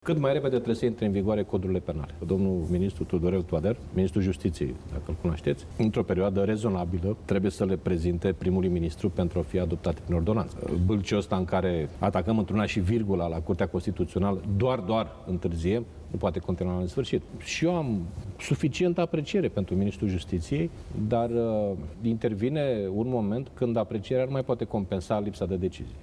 Liderul PSD i-a cerut joi seară, încă o dată, ministrului Tudorel Toader, o ordonanță de urgență privind Justiția. Liviu Dragnea, pentru Antena 3:
28dec-08-Dragnea-Tudorel-sa-dea-ordonanta.mp3